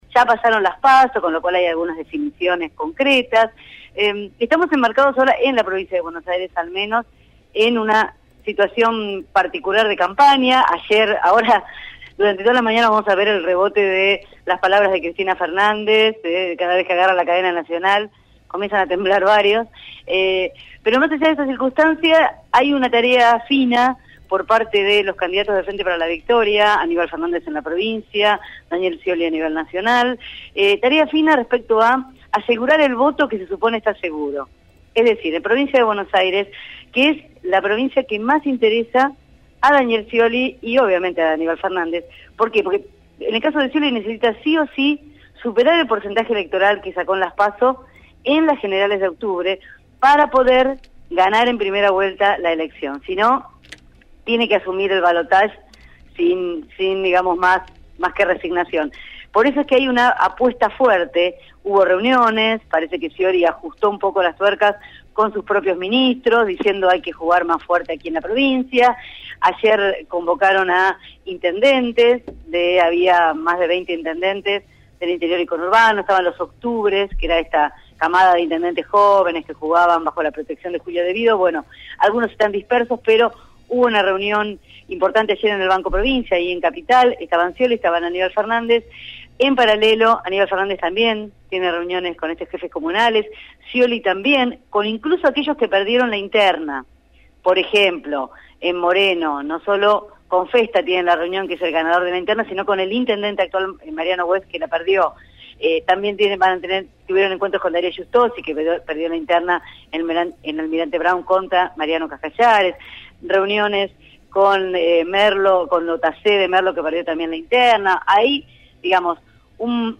realizó un informe sobre cómo Daniel Scioli encara esta nueva etapa de la campaña, de cara a las elecciones generales de octubre.